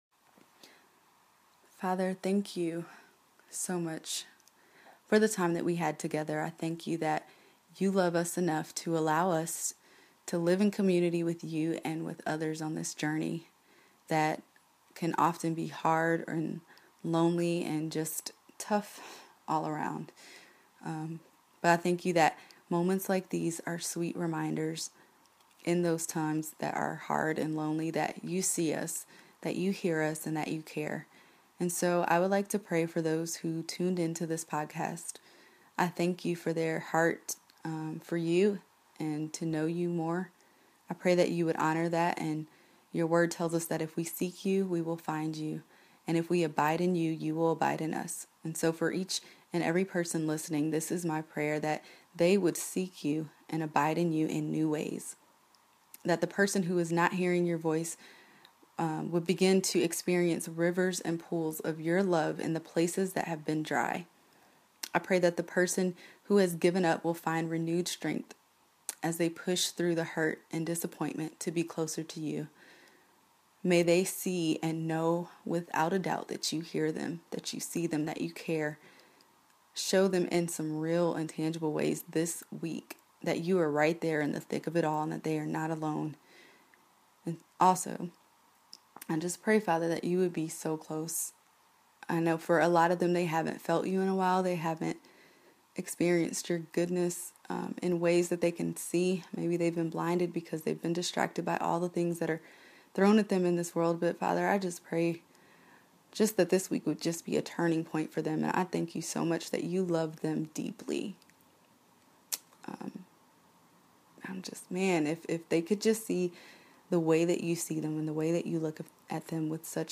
She even recorded this prayer just for you.
Prayer.mp3